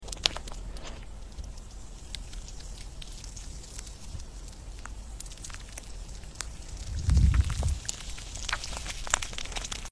This bubble looks Mp3 Sound Effect 🎧 Ready? This bubble looks innocent… but wait till you hear the pop!